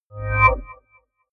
Royalty free music elements: Pads